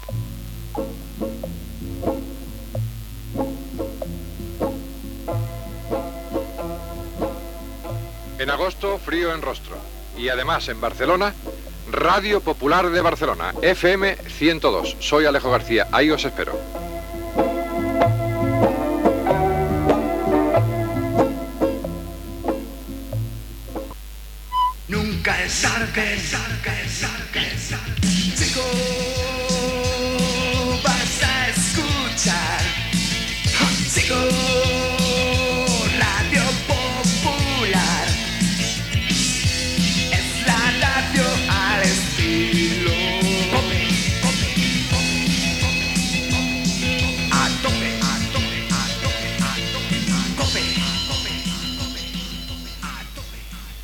Anunci de l'inici d'emissions de Radio Popular a Barcelona en FM el dia 1 d'agost, indicatiu de Radio Popular